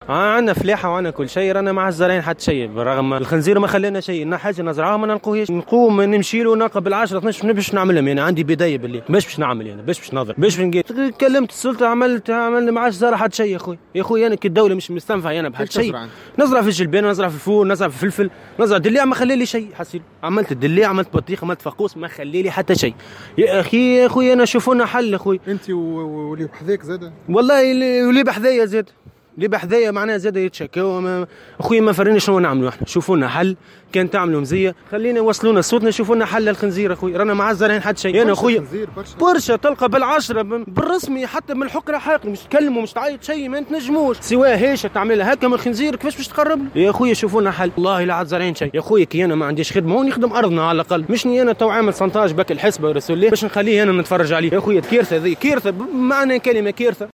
وتوجه فلاح بنداء عبر "الجوهرة أف أم" للسلطات المعنية للتدخل العاجل.